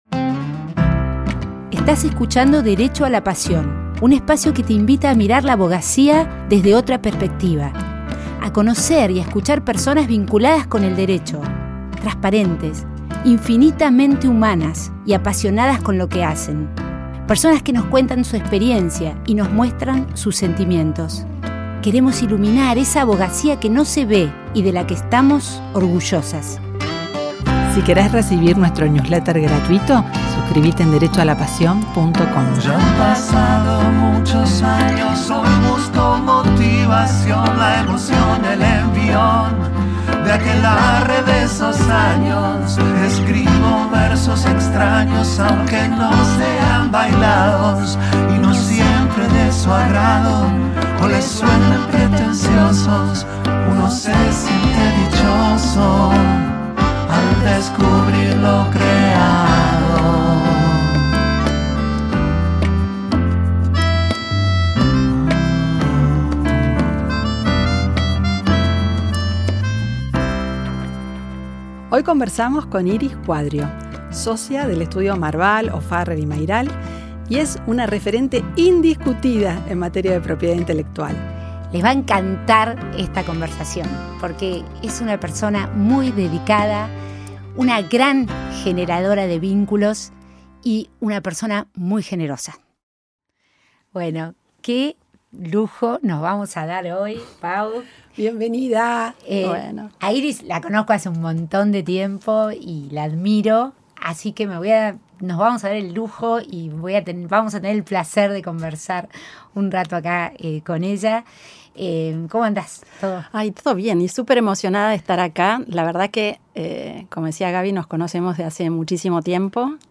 Y terminamos cantando.